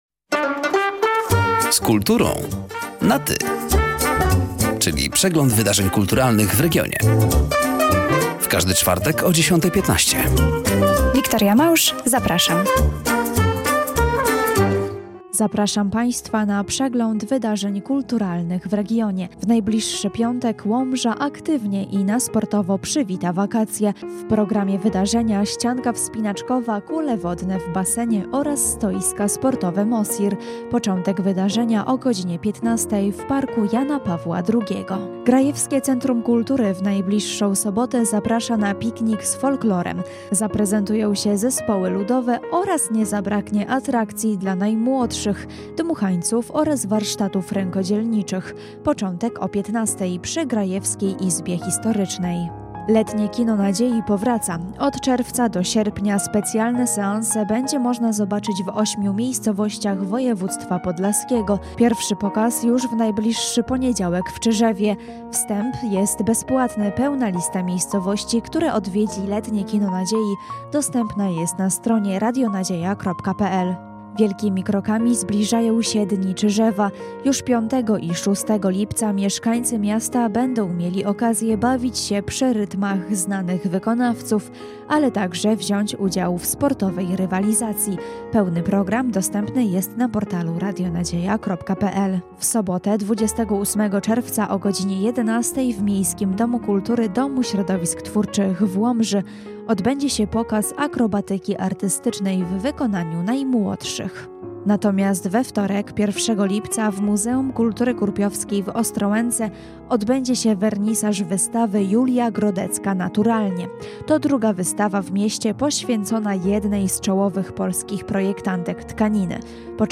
Zapraszamy do wysłuchania rozmowy i zapoznania się ze zbliżającymi wydarzeniami w regionie: